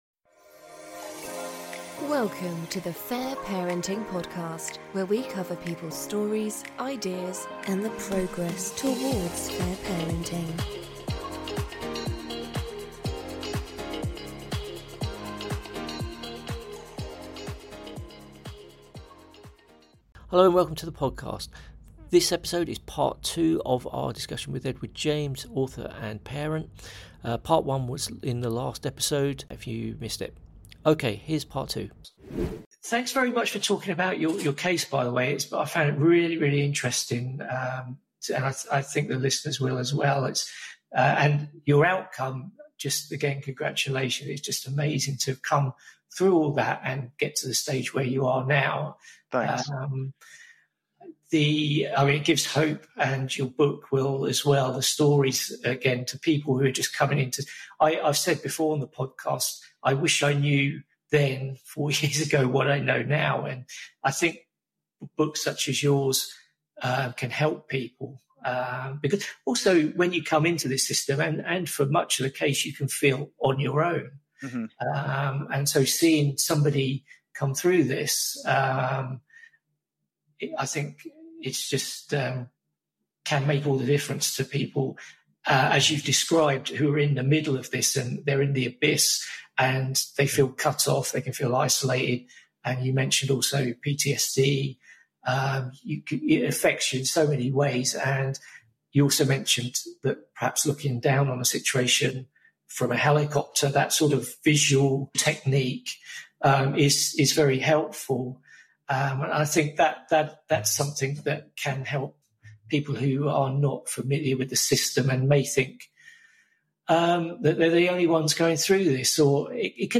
We speak on many topics and it is a long conversation which is why we published it in 2 parts, with part 1 in the previous episode.